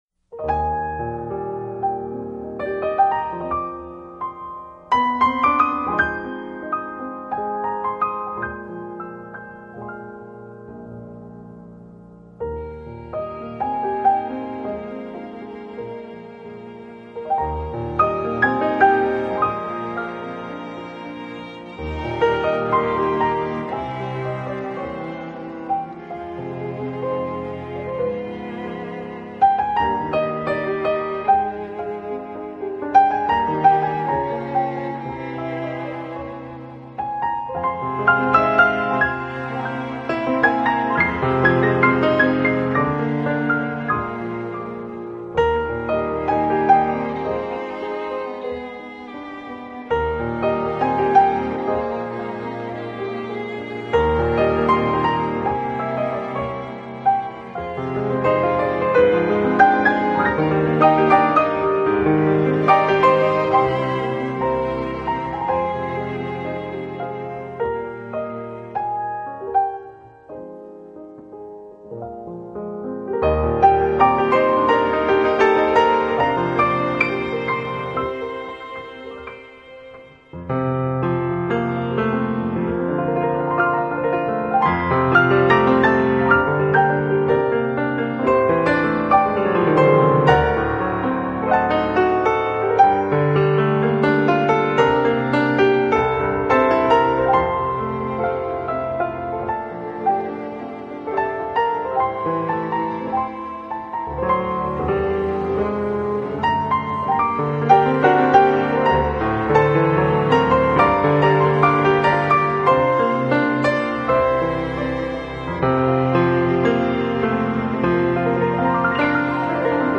音乐类型：钢琴
精心演绎的弦乐伴奏，一张令人爱不释手的钢琴专辑。